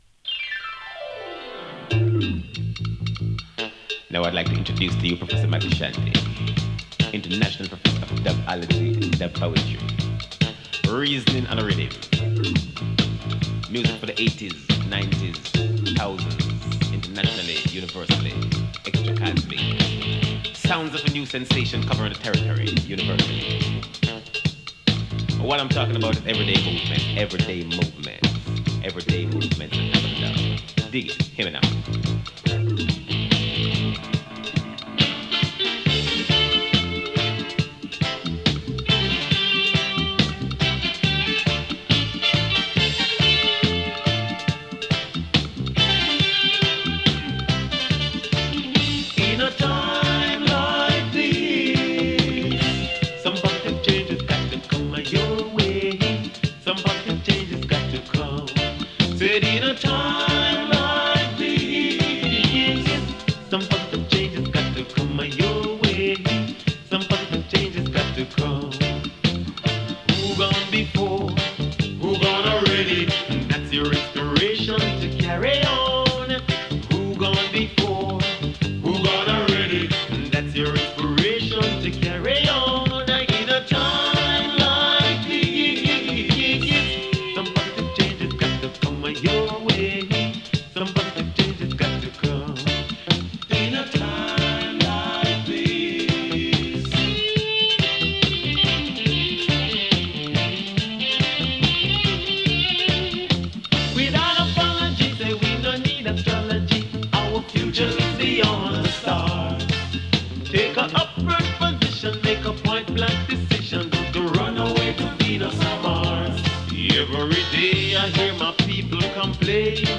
跳ねたリズムとメジャー/マイナーを行き来するメロディーが印象的な